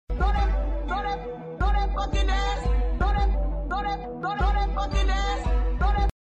Bean Dance Filter Sound sound effects free download